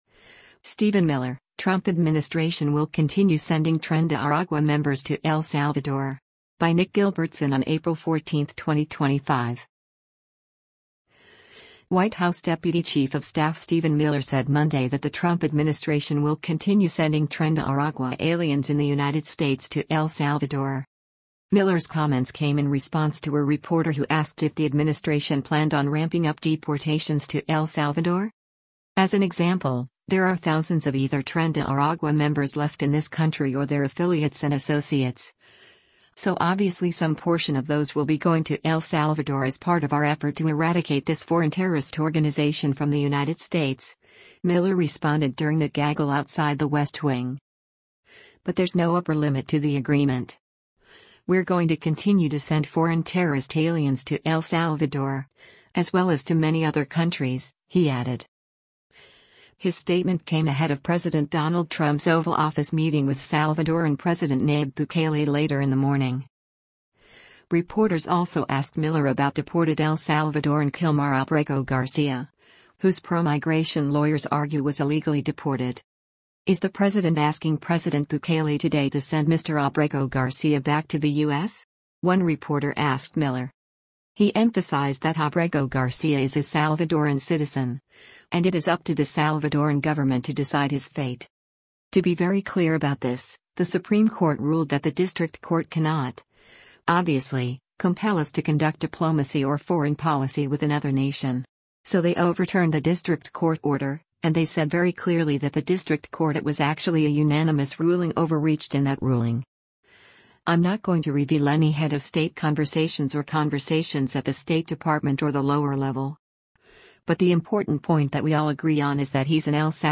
Miller’s comments came in response to a reporter who asked if the administration planned “on ramping up deportations to El Salvador?”